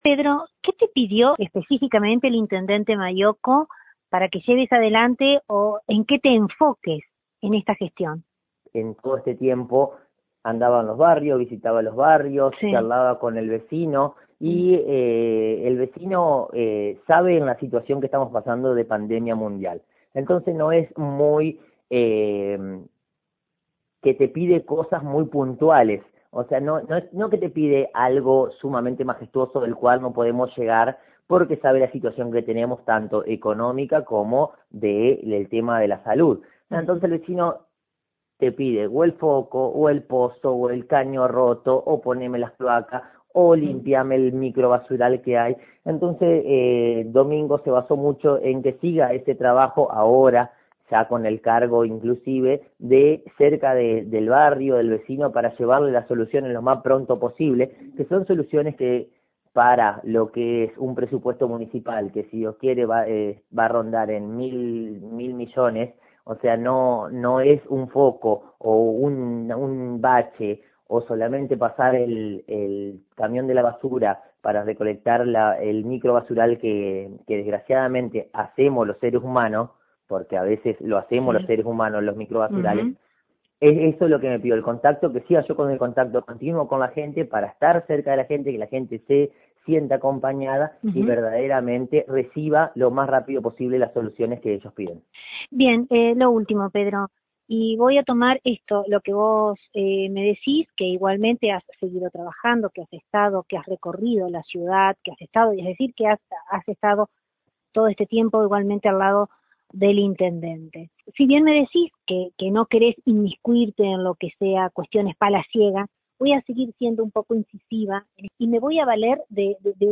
Dialogamos con el flamante Secretario de Gobierno municipal, el ex edil Pedro Mansilla